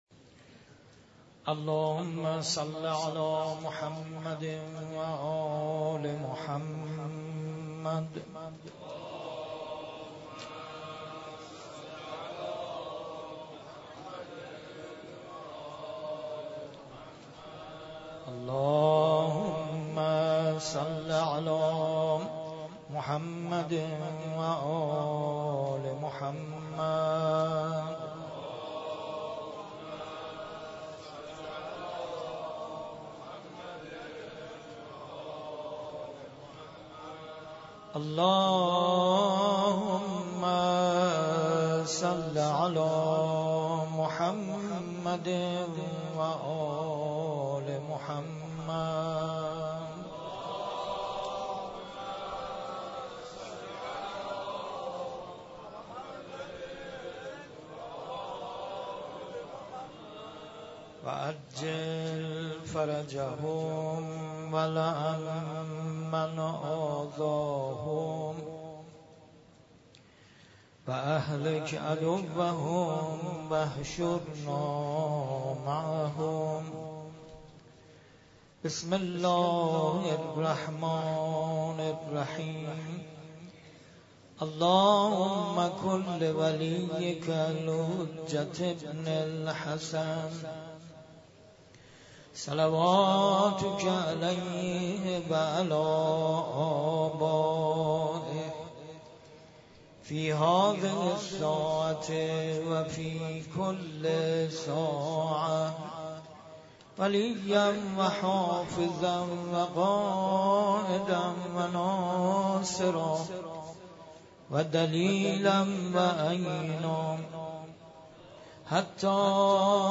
در مسجد شهدا برگزار گردید
قرائت دعای ابوحمزه (قسمت چهارم) ، روضه حضرت امام علی (علیه السلام)